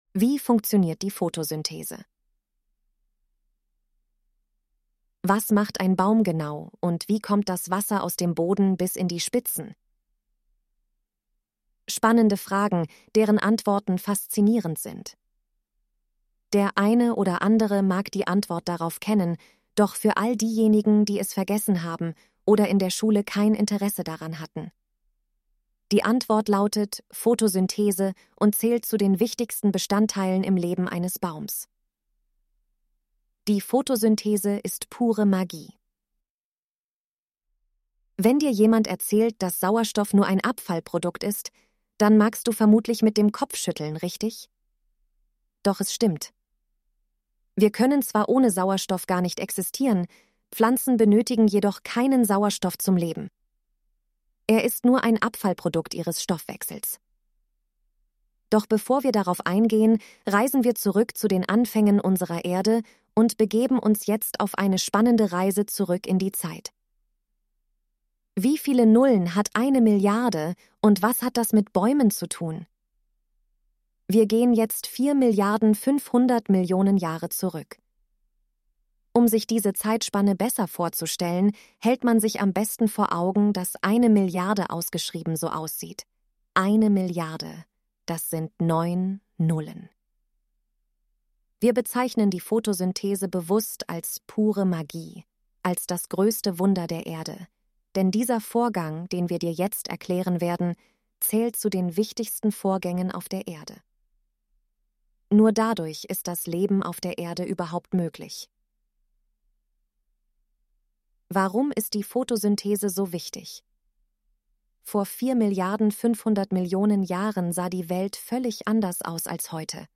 von Team baumbad 05.11.2020 Artikel vorlesen Artikel vorlesen Was macht ein Baum genau und wie kommt das Wasser aus dem Boden bis in die Spitzen?